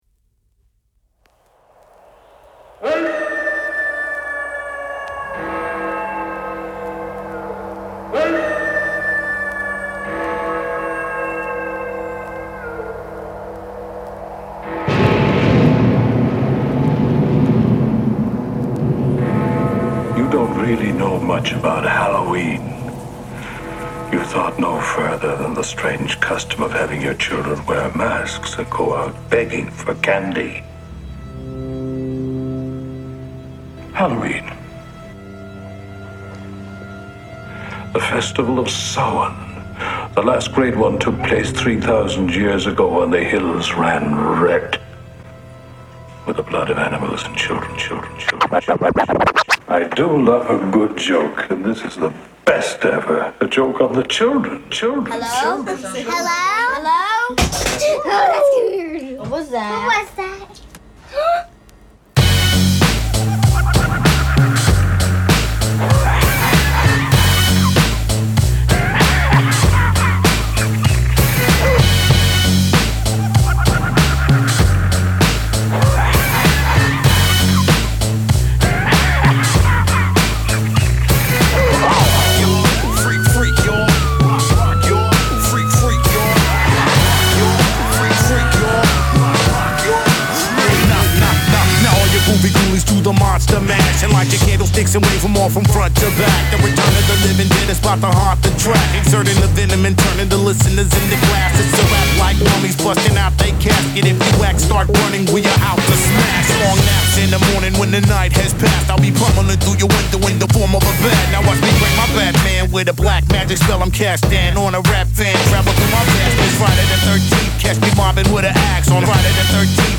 Genre: Turntablism Category